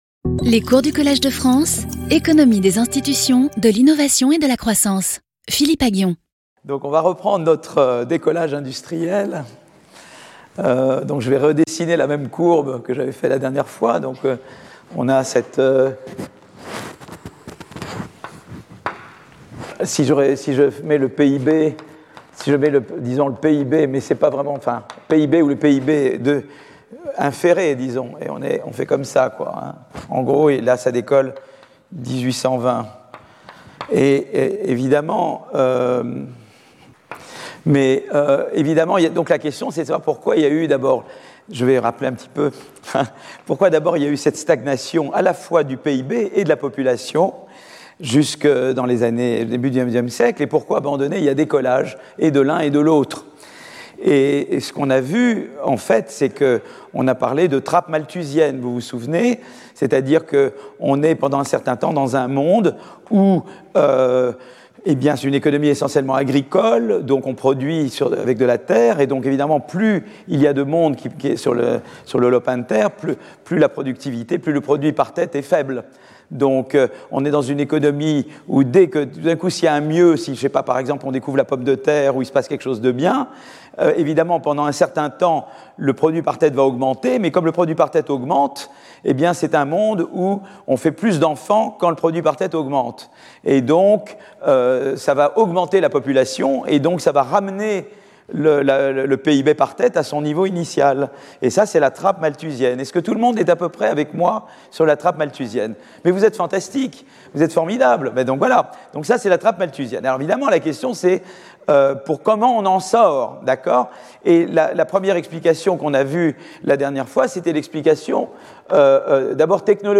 Intervenant(s) Philippe Aghion Professeur du Collège de France
Cours